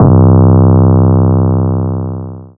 DEEDOTWILL 808 30.wav